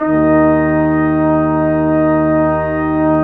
Index of /90_sSampleCDs/Roland LCDP06 Brass Sections/BRS_Quintet/BRS_Quintet % wh